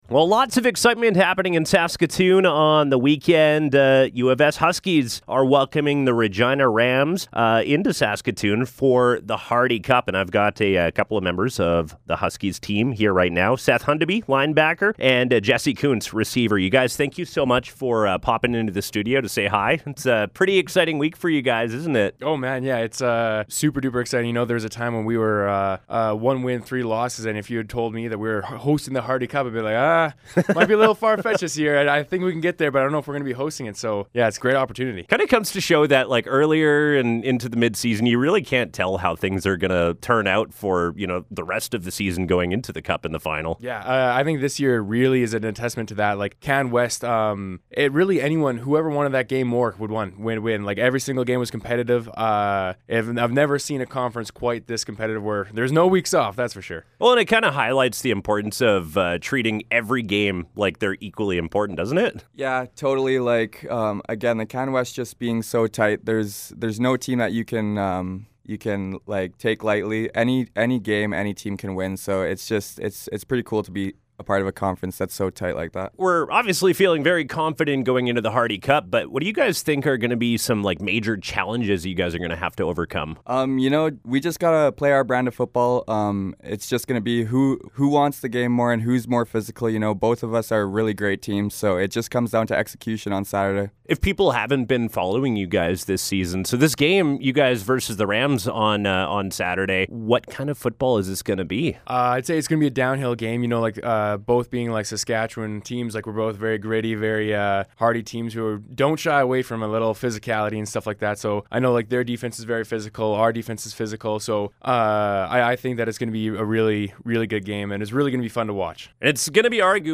Interview: Huskies Football (Hardy Cup)